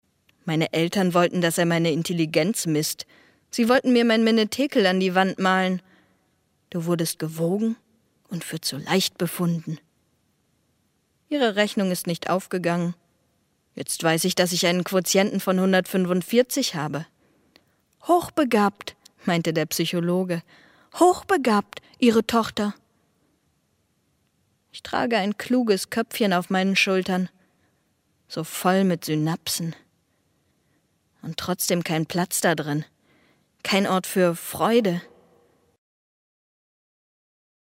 Profi - Sprecherin mit junger, variabler Stimme von naiv bis kompetent
Kein Dialekt
Sprechprobe: Werbung (Muttersprache):